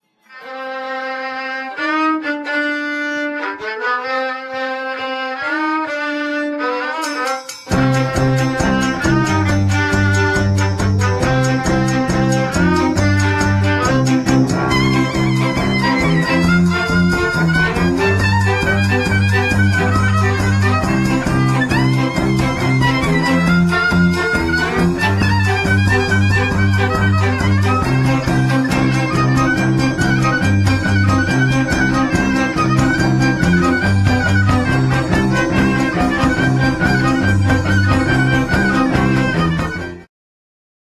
skrzypce
skrzypce, vioara cu guarna
cymbały, altówka, kaval, gordon
wiolonczela
bęben mołdawski